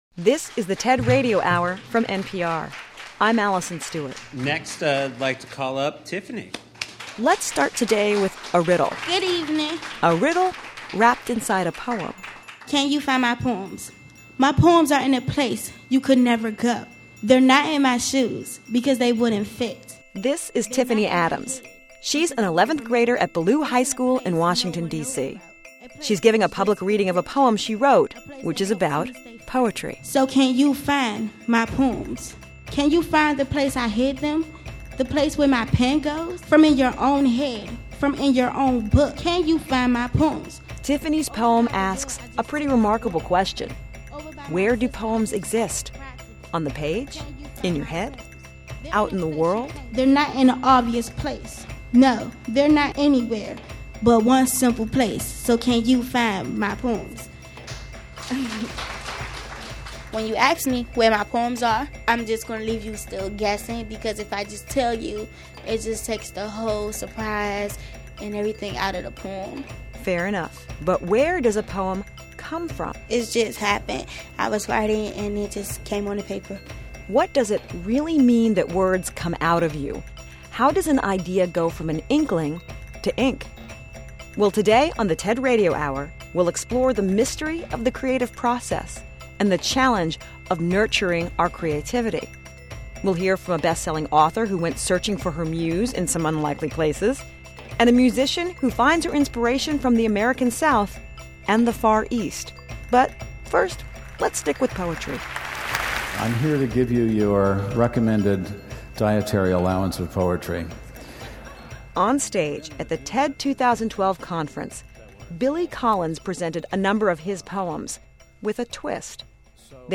In this hour we’ll hear from some TED speakers who explain their craft and the daily challenge of nurturing creativity.
Former U.S. Poet Laureate Billy Collins explains his writing process involves plenty of patience, intensity, and trips to the dry cleaners. Author Elizabeth Gilbert muses on the definition of genius and singer/songwriter Abigail Washburn blends the Far East with Appalachia with her banjo.